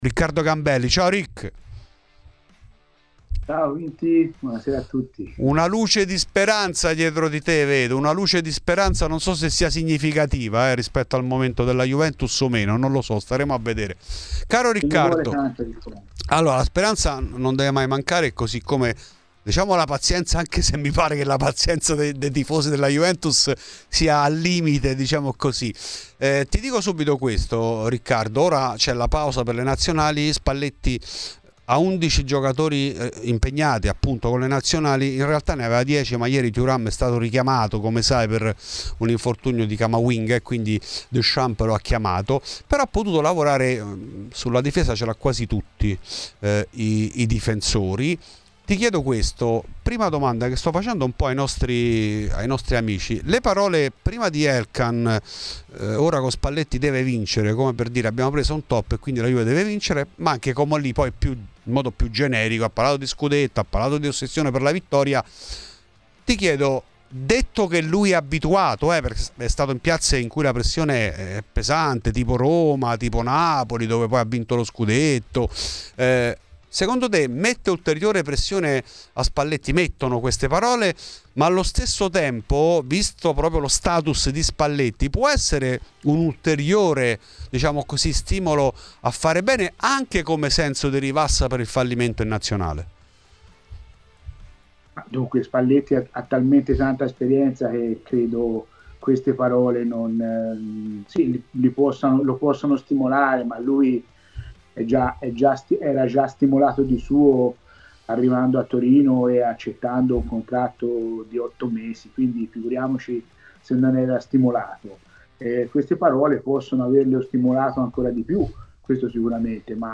Ospite di Radio Bianconera , durante Fuori di Juve